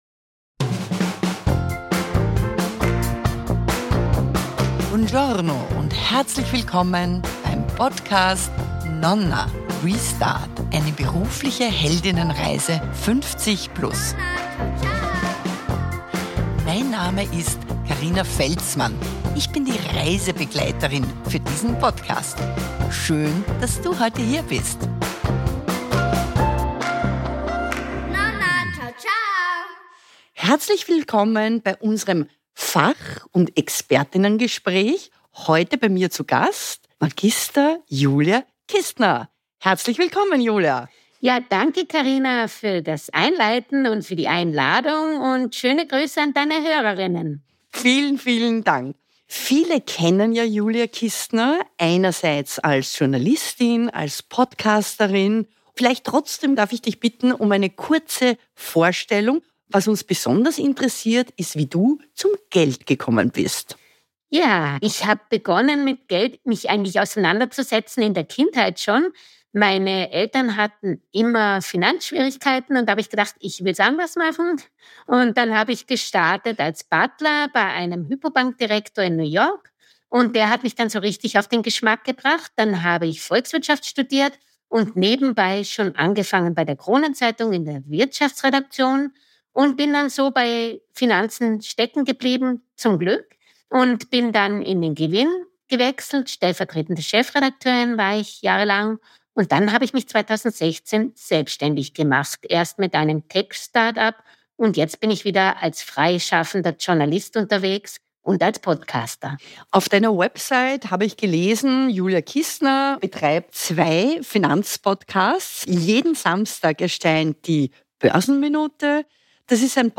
Im Fachinterview lernen wir grundlegende Begriffe verstehen, was sind Aktien, Anleihen, ETFs und Investmentfonds. Was sind die Unterschiede, für wen machen welche Strategien Sinn? Oder doch in die eigene Immobilie investieren?